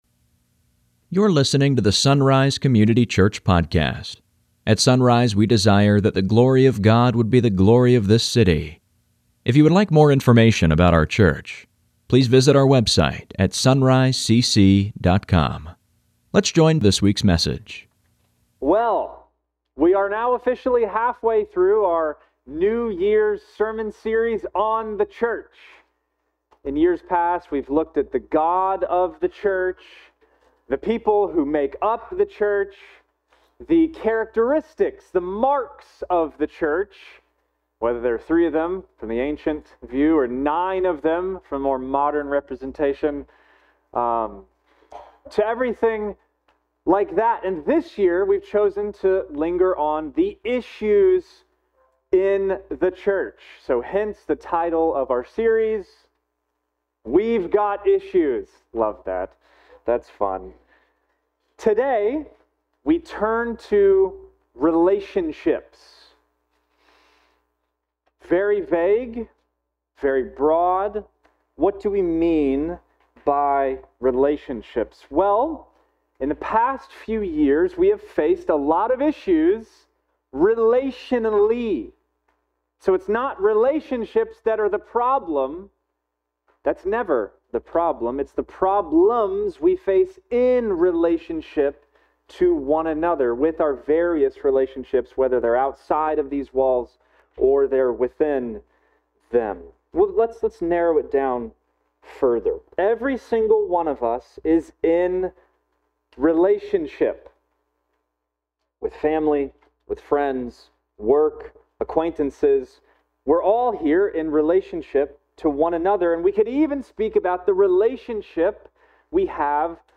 Sunday Mornings | SonRise Community Church
Well we’re now halfway through our new year sermon series on the Church, titled ‘We’ve Got Issues’, in which we’re examining what we think have been the biggest issues we here at SonRise and Christians in general have struggled with throughout the past few years.